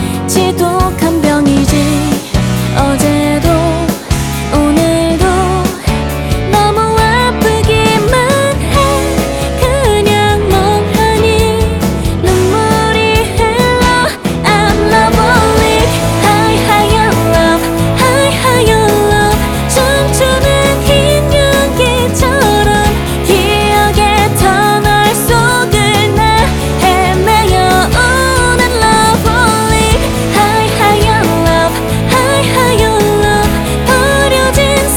K-Pop Pop Rock
Жанр: Поп музыка / Рок